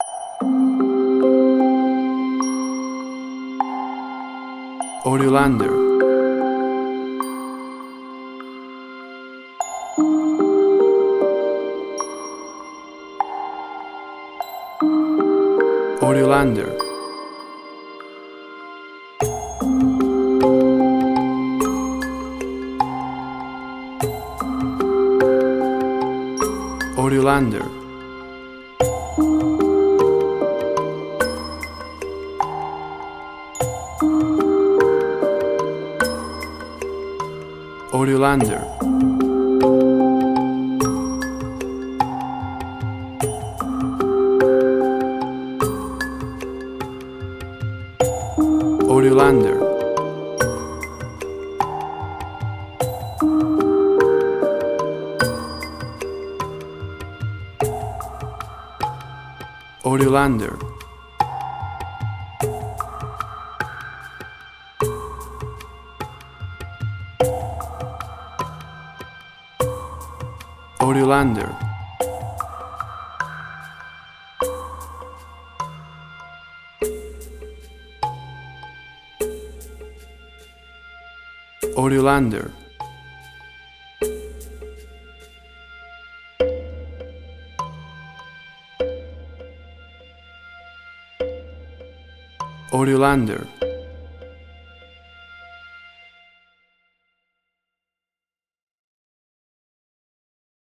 WAV Sample Rate: 24-Bit stereo, 48.0 kHz
Tempo (BPM): 74